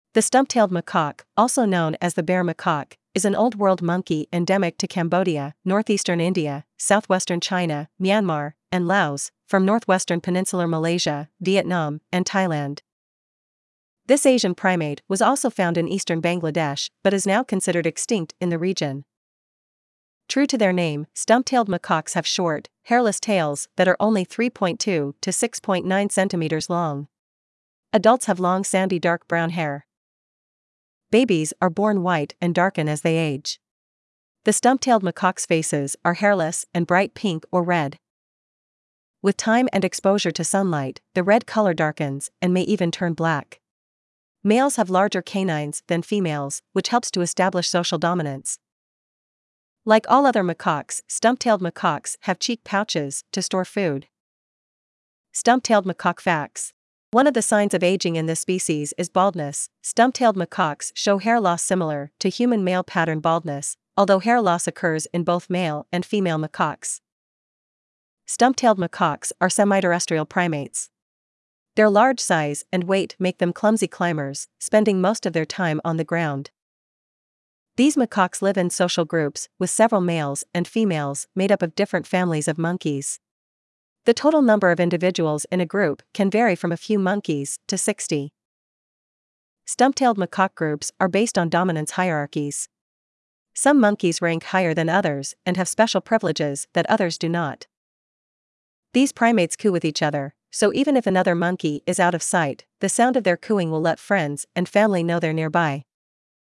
Stump-tailed Macaque
• These primates “coo” with each other, so even if another monkey is out of sight, the sound of their cooing will let friends and family know they’re nearby.
stump-tailed-macaque.mp3